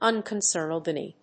ùn・con・cérn・ed・ly /‐nɪdli/
発音記号
• / ‐nɪdli(米国英語)